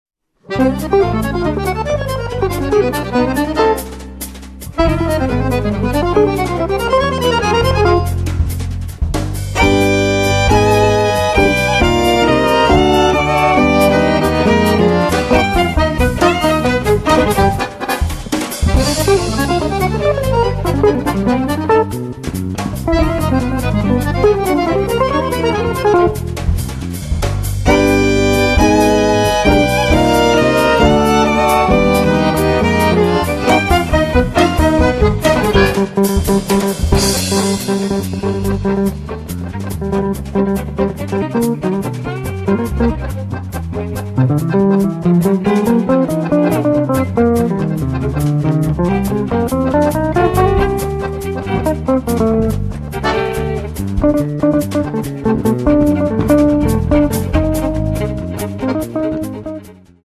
violin
accordion
cello
electric guitar
electric bass
drums